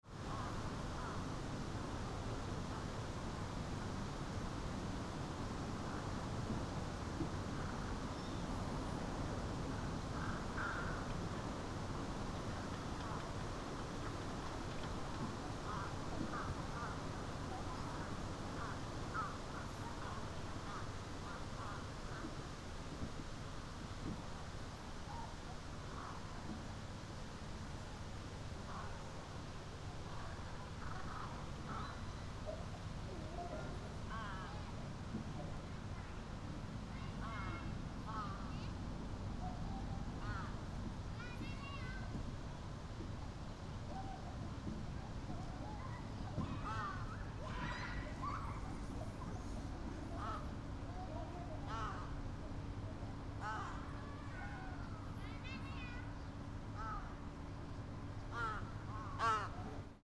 Fukushima Soundscape: Shinhama Park
Even though the weather was extremely hot in June, around 10 children were playing cheerfully in Shinhama Park. ♦ Some crows were cawing around the park.